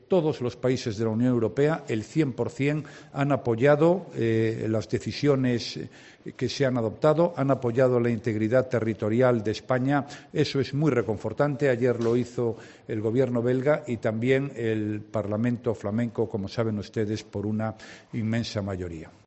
Rajoy, en declaraciones a los periodistas en Salamanca, se ha referido de esta forma a las previsiones macroeconómicas para España hechas públicas este jueves por la Comisión Europea.